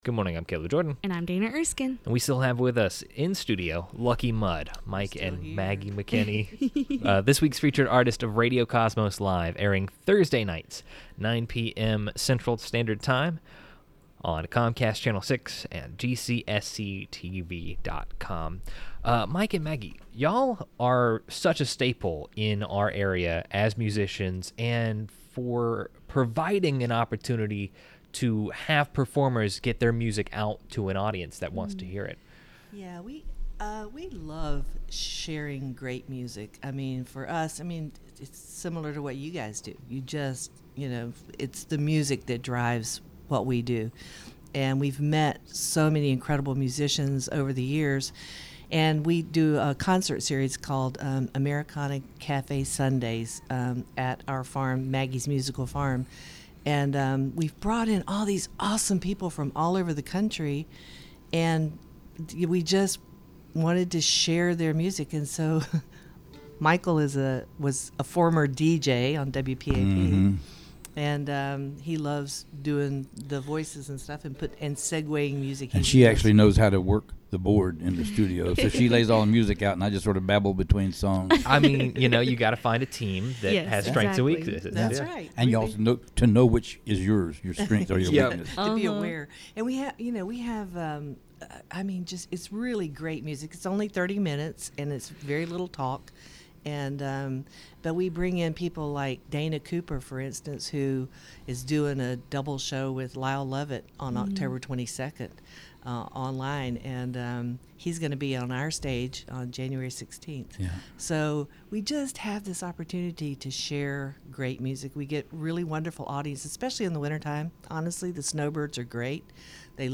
They performed their song titled,  “Beach Town”, and how the song was written.